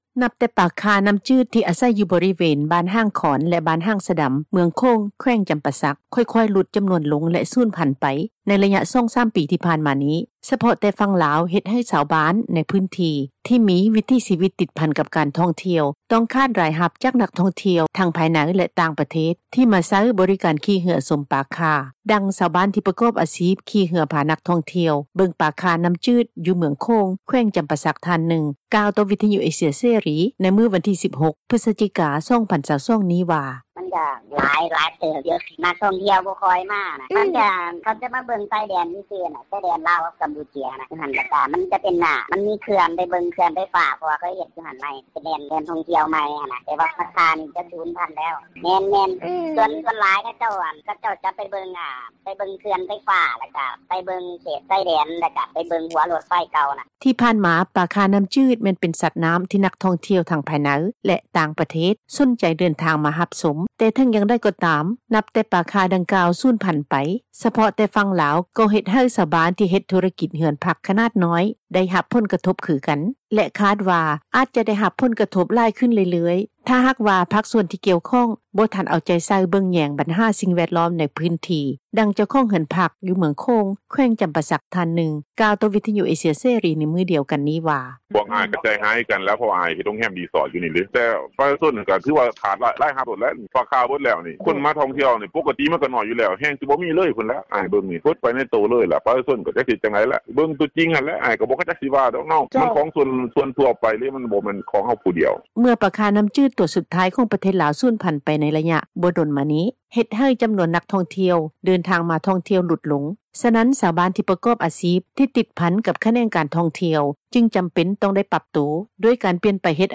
ດັ່ງ ຊາວບ້ານ ທີ່ປະກອບອາຊີບ ຂີ່ເຮືອພານັກທ່ອງທ່ຽວ ເບິ່ງປາຂ່ານ້ໍາຈືດ ຢູ່ເມືອງໂຂງ ແຂວງຈໍາປາສັກ ທ່ານນຶ່ງ ກ່າວຕໍ່ວິທຍຸ ເອເຊັຽເສຣີ ໃນມື້ວັນທີ 16 ພຶສຈິກາ 2022 ນີ້ວ່າ:
ດັ່ງ ໄກ໋ດ໌ ນໍາທ່ຽວ ຢູ່ບ້ານສີພັນດອນ ເມືອງໂຂງ ແຂວງຈໍາປາສັກ ທ່ານນຶ່ງ ກ່າວວ່າ: